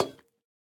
Minecraft Version Minecraft Version latest Latest Release | Latest Snapshot latest / assets / minecraft / sounds / block / copper_bulb / step6.ogg Compare With Compare With Latest Release | Latest Snapshot
step6.ogg